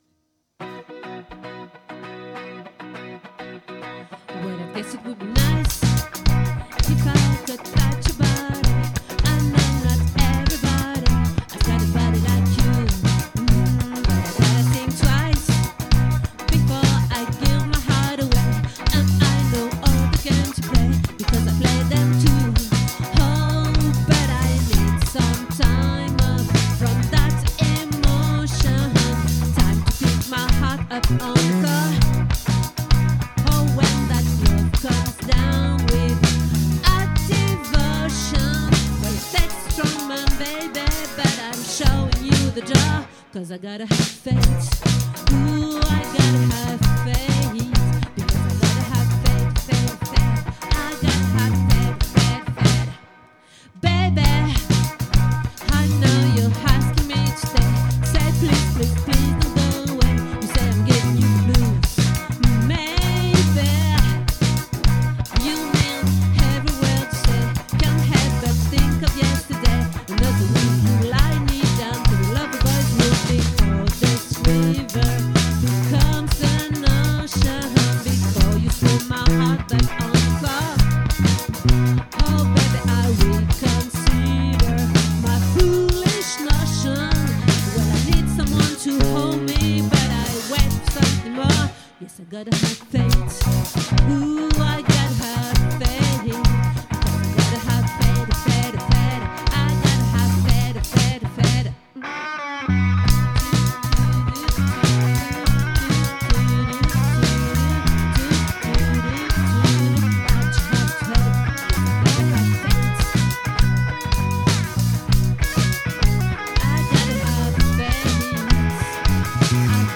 🏠 Accueil Repetitions Records_2025_12_22